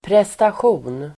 Uttal: [prestasj'o:n]